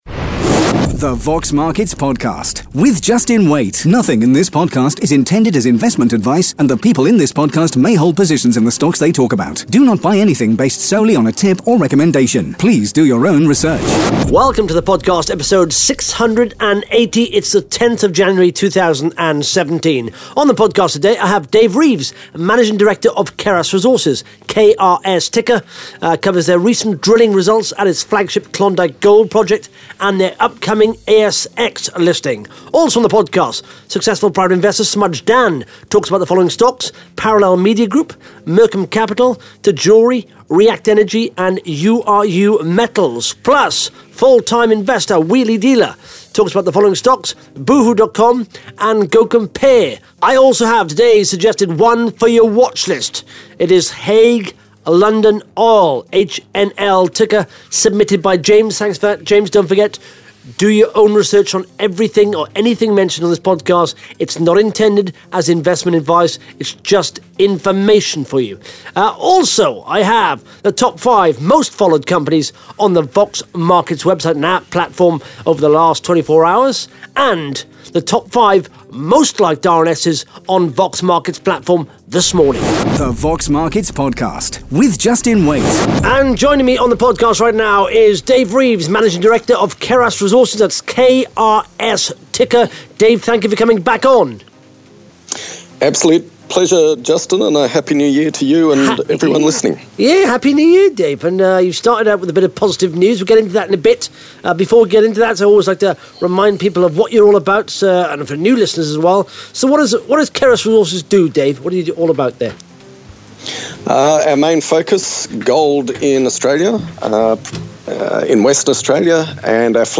(Interview starts at 1 minutes 22 seconds)